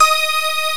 PAD 1990 3.wav